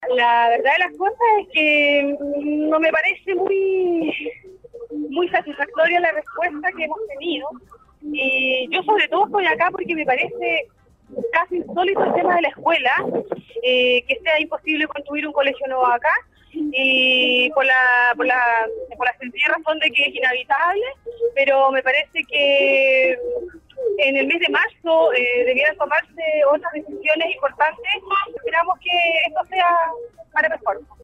También otra vecina de la localidad se manifestó todavía disconforme con la salida del gobernador, señalando que en marzo debe haber otras determinaciones que permitan a la provincia de Palena avanzar en sus necesidades.